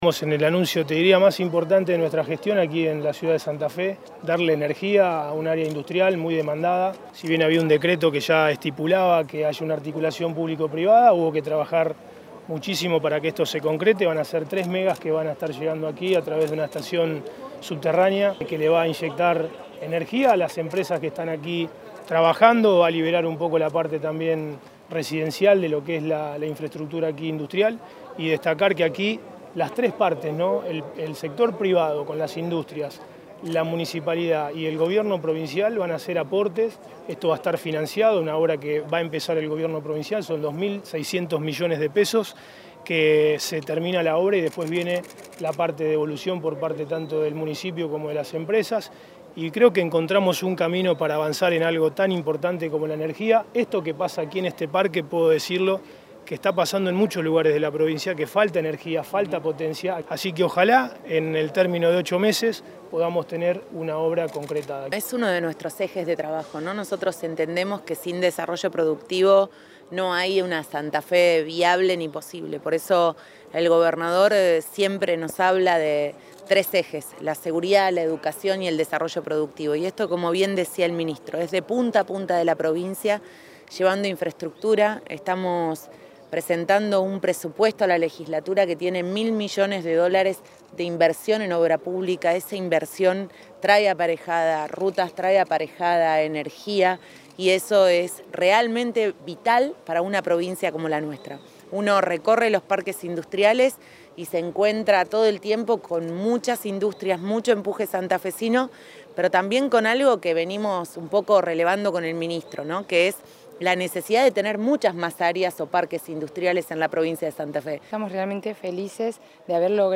Declaraciones Scaglia y Puccini